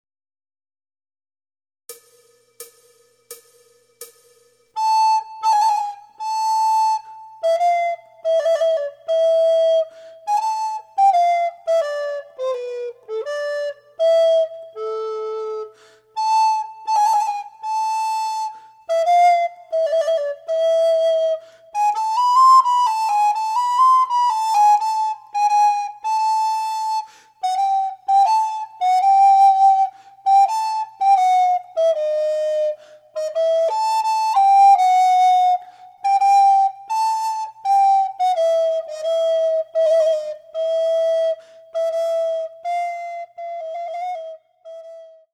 アルトリコーダーデュオソナタ
演奏例は、ほとんどがアマチュアのリコーダー奏者によるものです。
第１リコーダー用マイナスワン